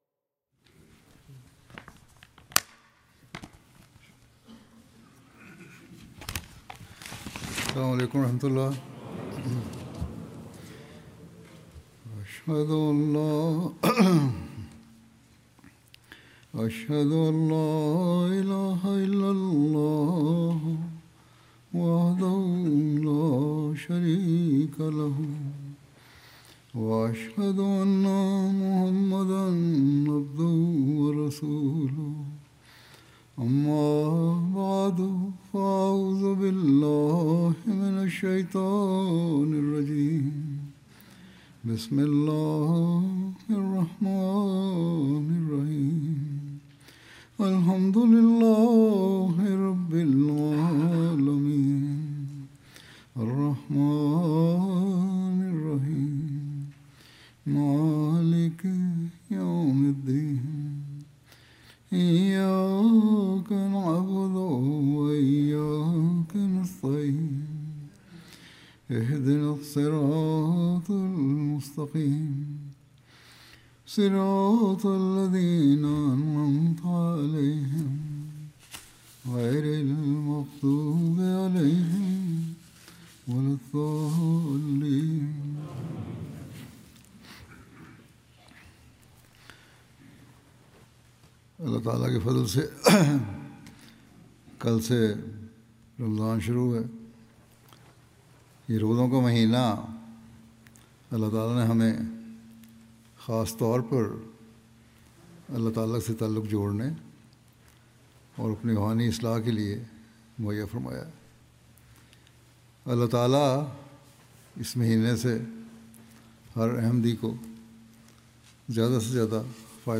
20 February 2026 Ramadan: A Path to Enduring Nearness to Allah and Lifelong Self-Reformation Urdu Friday Sermon by Head of Ahmadiyya Muslim Community 50 min About Urdu Friday Sermon delivered by Khalifa-tul-Masih on February 20th, 2026 (audio)